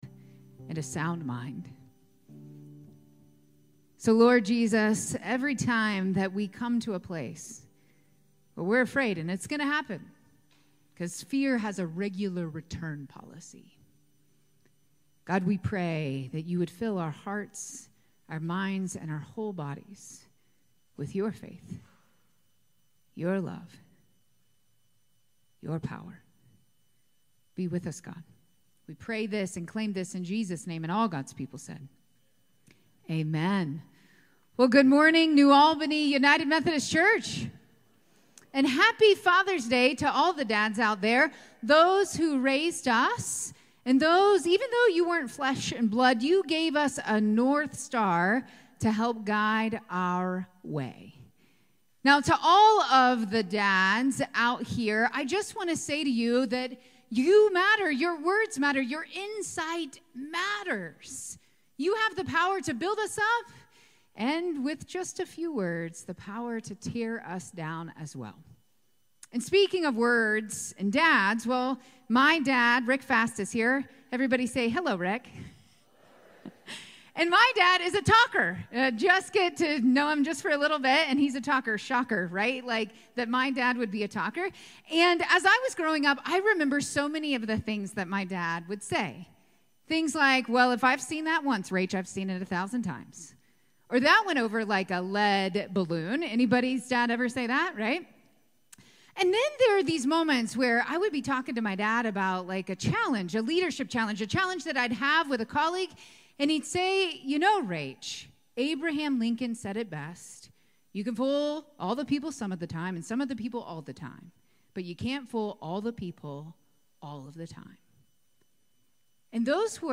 June 16, 2024 Sermon